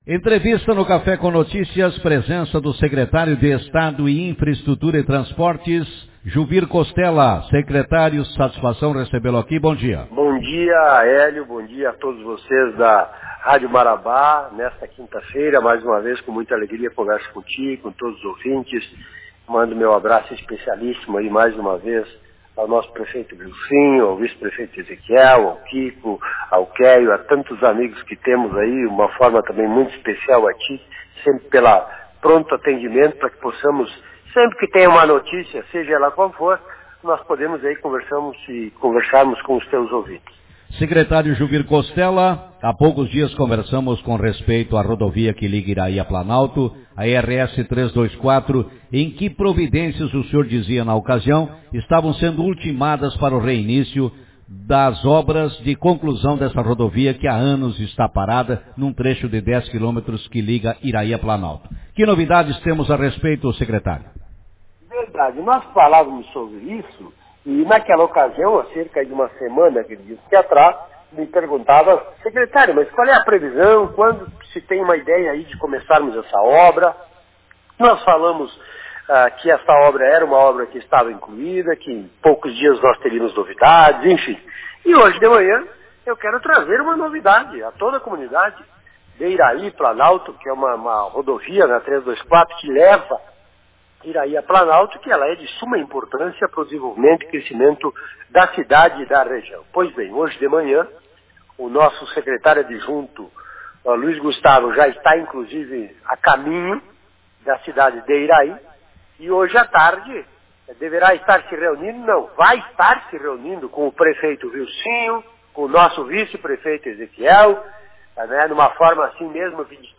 Na manhã desta quinta-feira,25, o Secretário de Logística e Transportes do Estado, deputado Juvir Costella, participou do programa Café com Notícias e anunciou o recomeço das obras de asfaltamento de parte da rodovia ERS-324 que liga Iraí a Planalto.